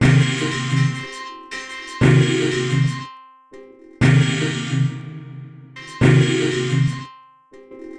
Abstract Rhythm 13.wav